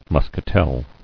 [mus·ca·tel]